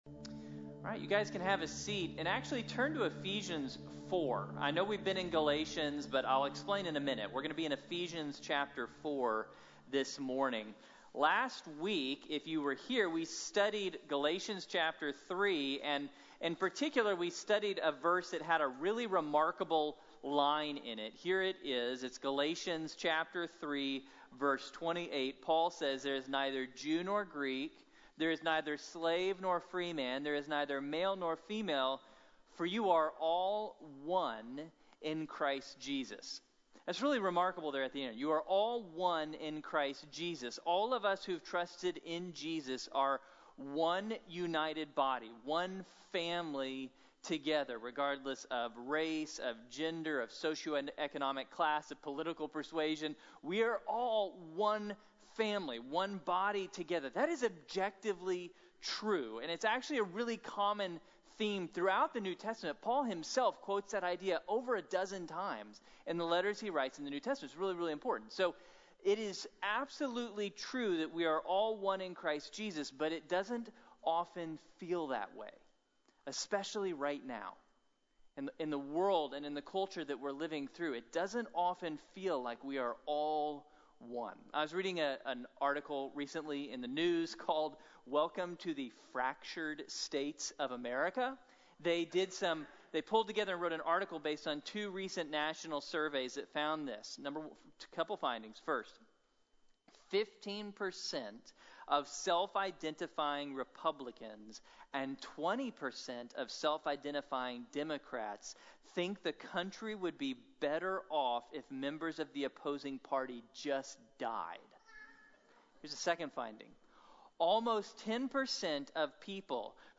Unified | Sermon | Grace Bible Church